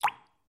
Звуки бульк
Мелкий предмет бросили в воду